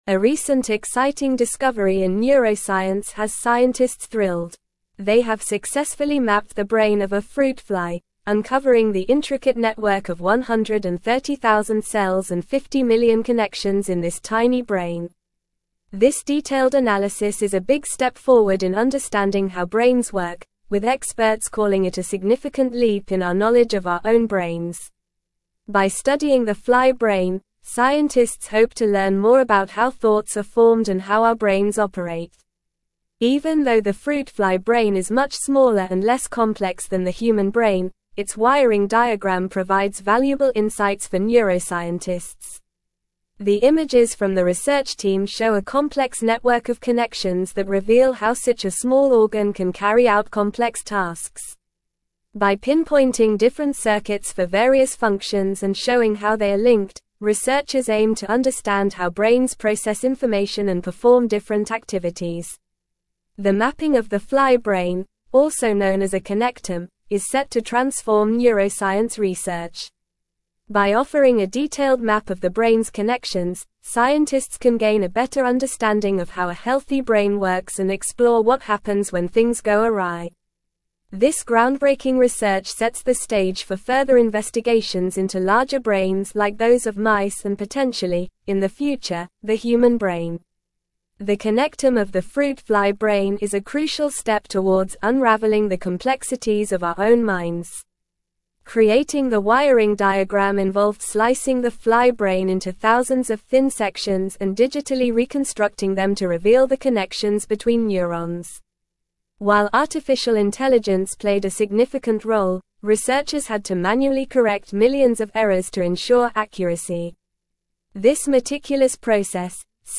Normal